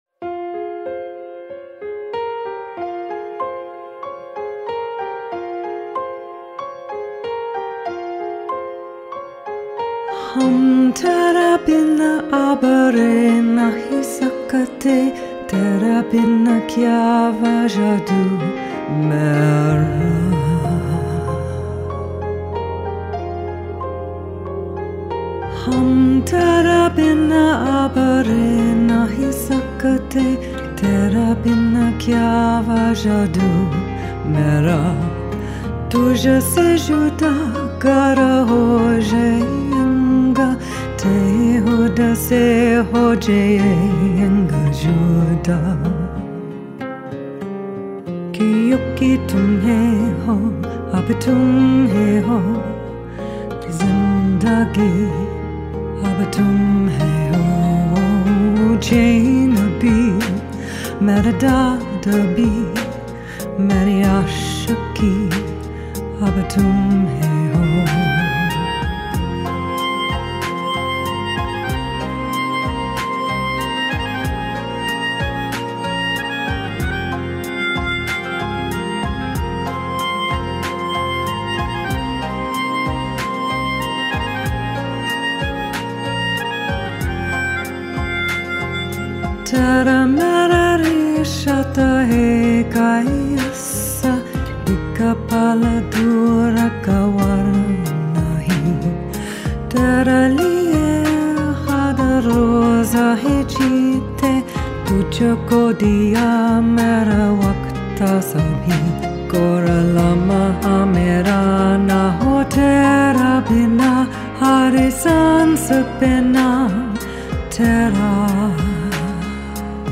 Demo tracks recorded & mixed in Vancouver, BC Canada at:
Studio Recorded October, 2017